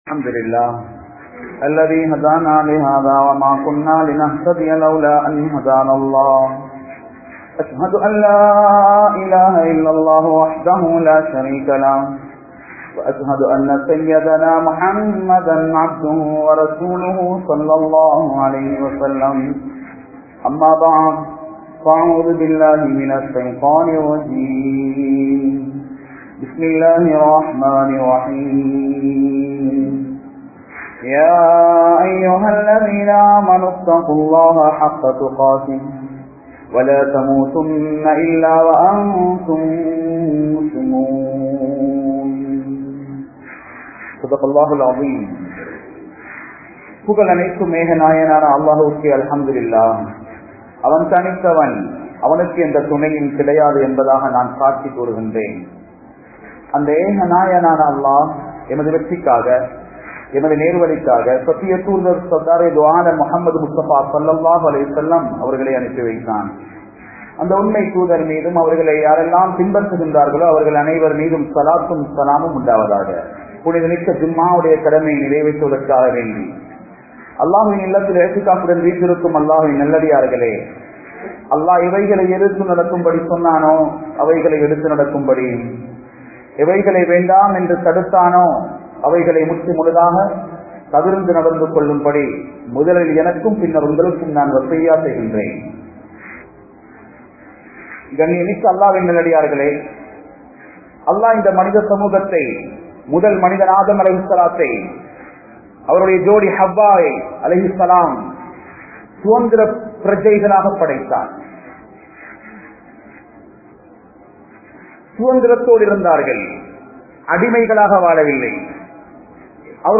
Suhantham Veesum Suthanthiram (சுகந்தம் வீசும் சுதந்திரம்) | Audio Bayans | All Ceylon Muslim Youth Community | Addalaichenai